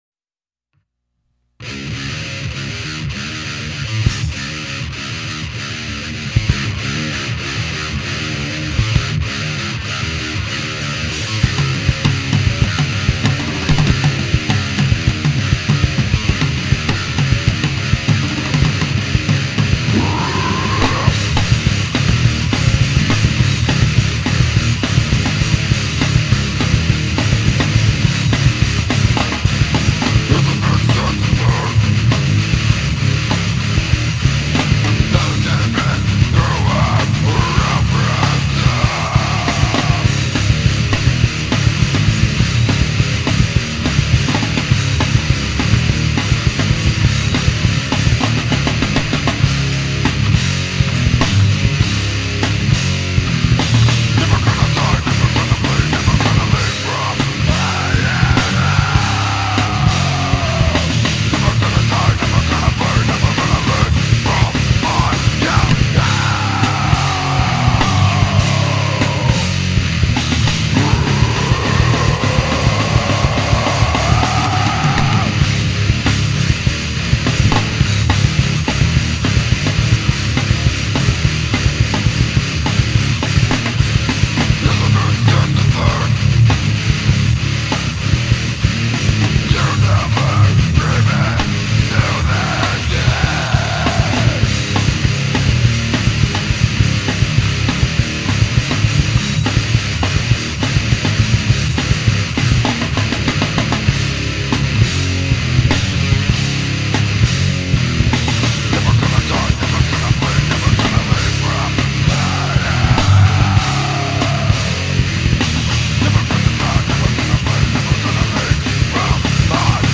All MP3's on site contain edited tracks.